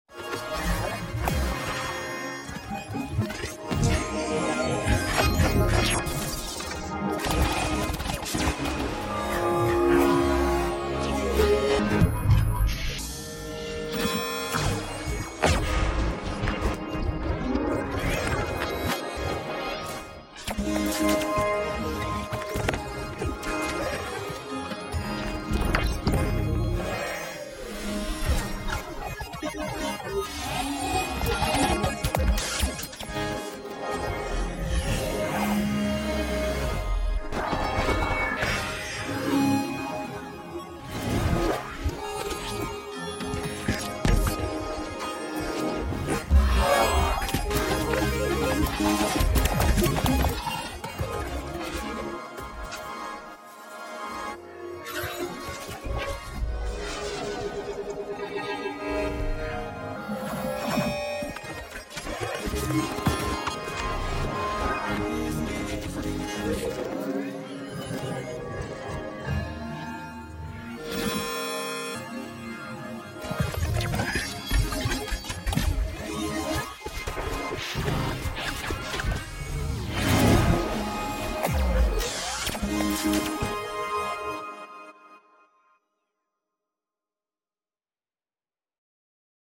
For those unfamiliar: the arrangement you’re hearing is being generated in real time by the patch you see running on my computer. It may not be more than a glorified sample playback system, but its output speaks to me and I think that’s all that matters. I loaded it with a few excerpts from never-to-be-finished compositions written earlier this year.